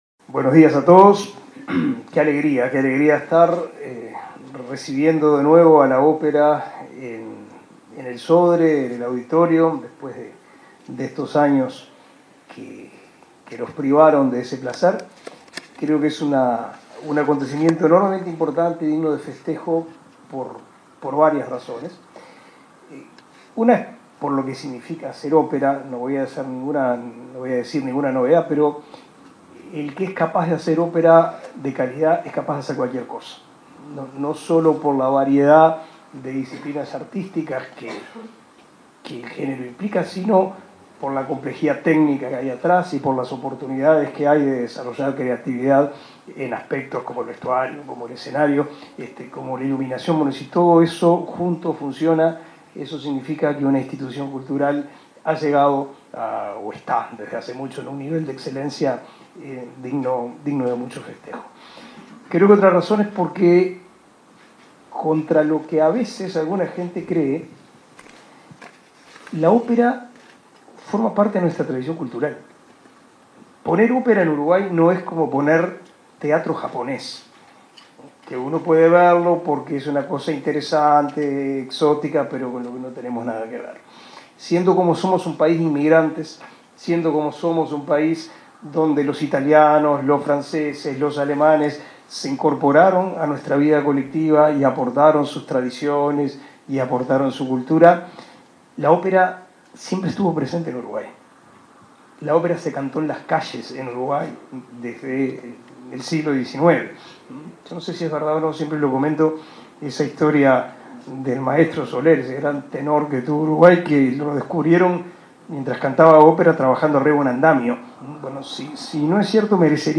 Palabras del ministro de Educación y Cultura, Pablo da Silveira
El 3 de agosto se realizó el lanzamiento la producción lírica del Coro Nacional del Sodre y el Conjunto Nacional de Música de Cámara "Il Campanello",
El ministro Pablo da Silveira participó del evento.